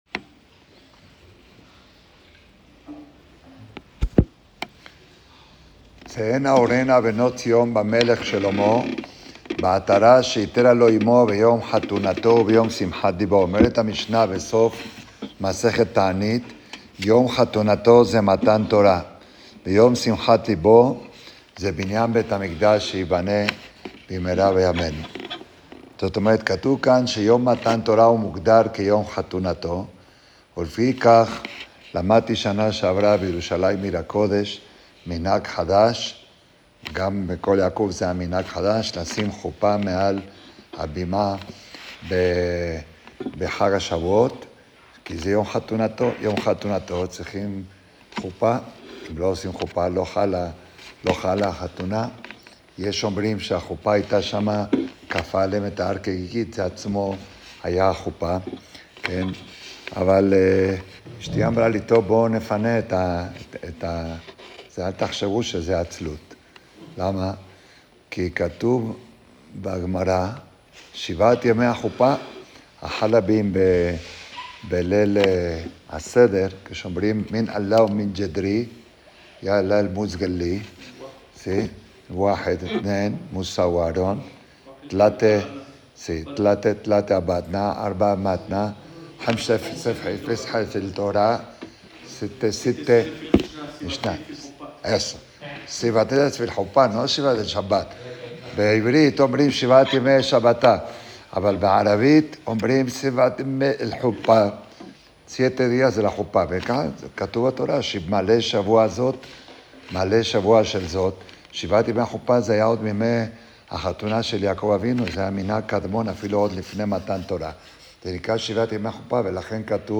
שיחה לאברכים 1 סיון התשפה חלק א׳ יום חתונתו שבעת ימי החופה